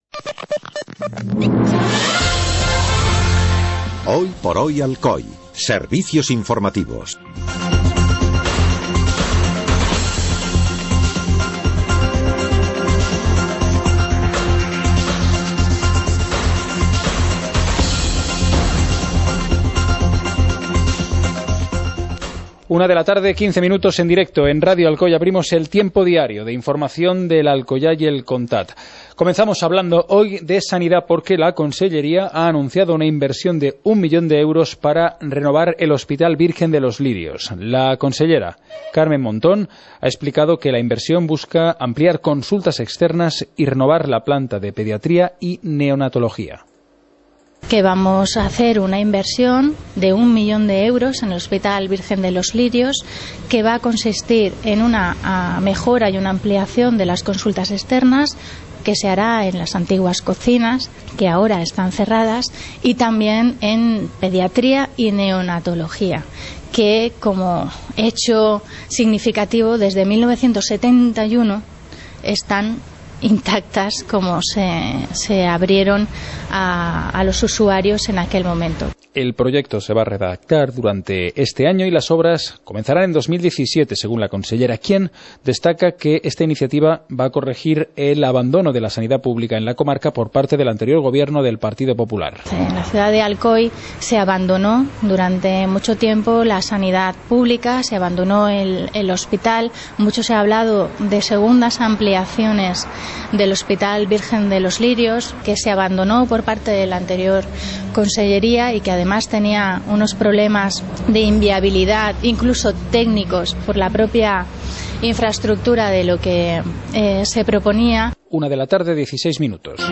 Informativo comarcal - jueves, 31 de marzo de 2016